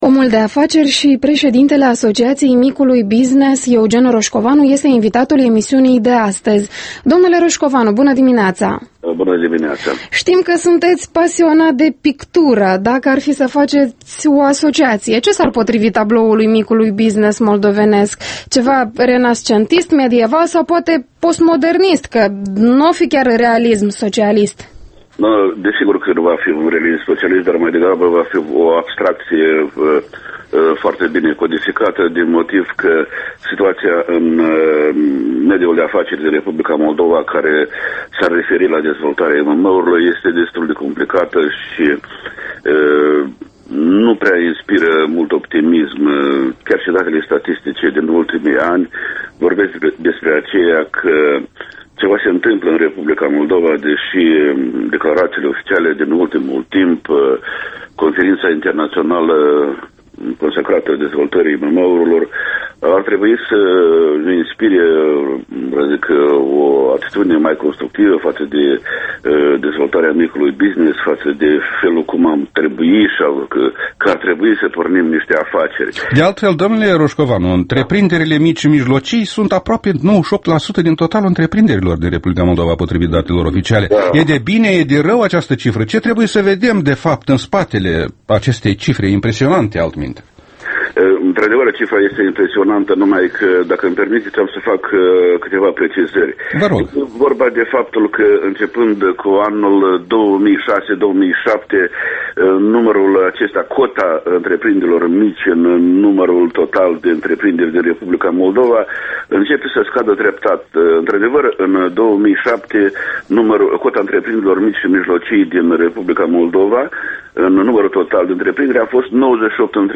Interviul matinal EL